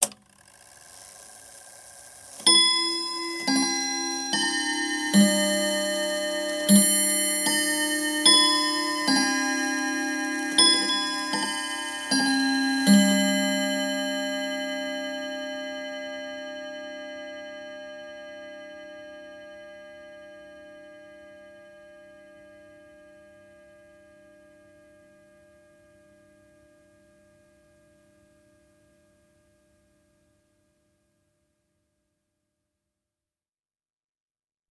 clock three-quarter hour chime.ogg